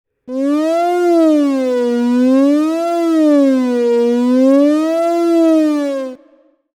achtbaan_2
achtbaan_2.mp3